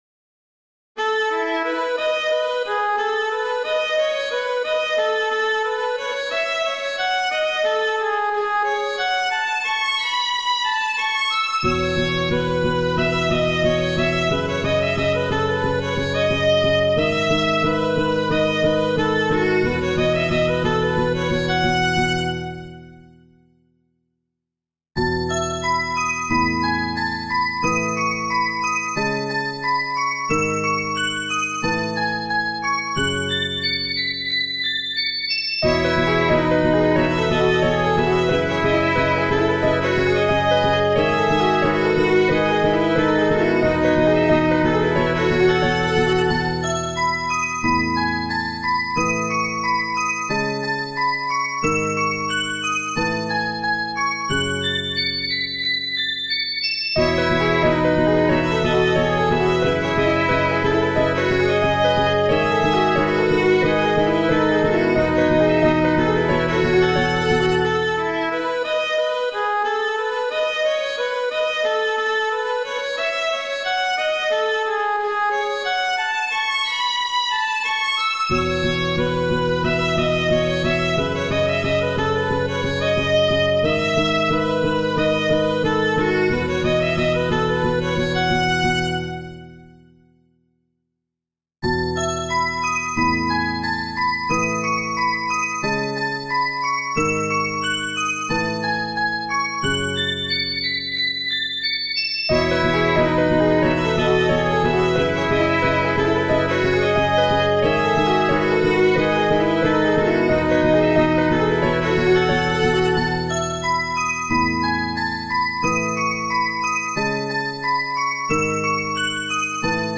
ジャンル：インストゥルメンタル